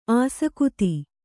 ♪ āsakuti